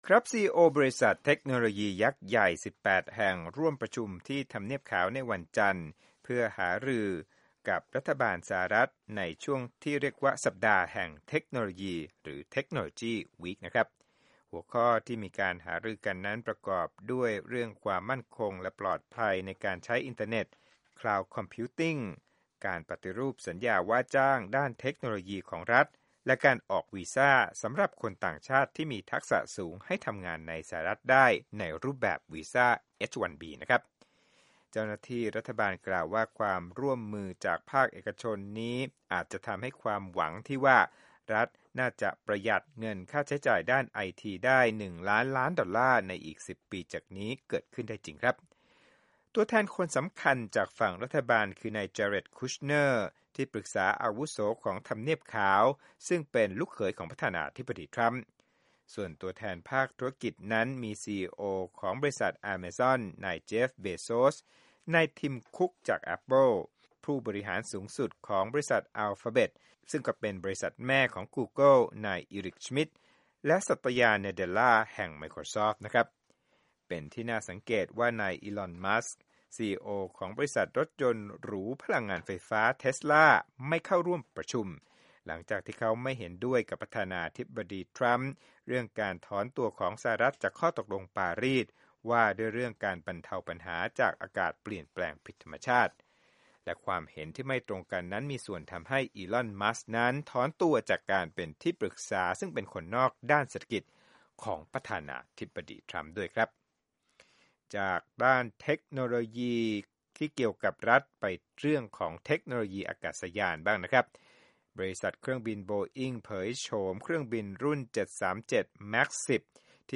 ข่าวธุรกิจ